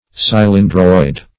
cylindroid - definition of cylindroid - synonyms, pronunciation, spelling from Free Dictionary
Cylindroid \Cyl"in*droid\ (s?l"?n-droid), n. [Gr. ky`lindros